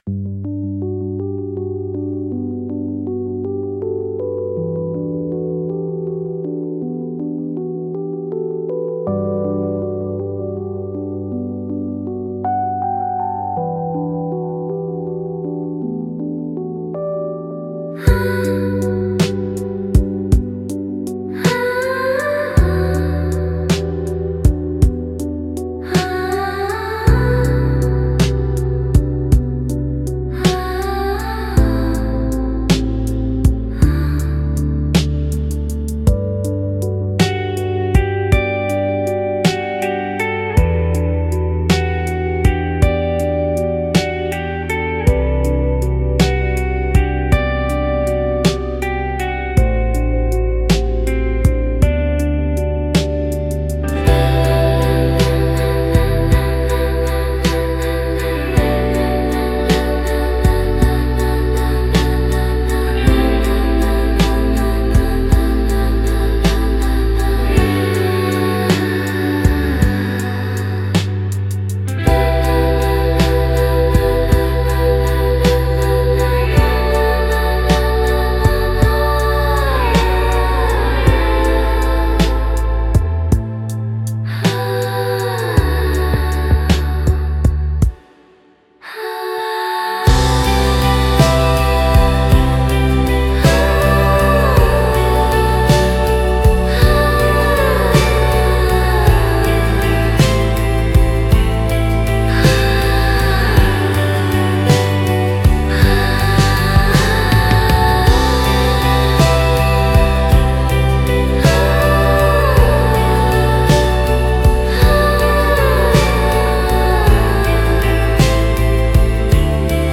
ドリームポップは、繊細で幻想的なサウンドが特徴のジャンルです。
静かで美しい音の重なりが心地よく、感性を刺激しながらも邪魔にならない背景音楽として活用されます。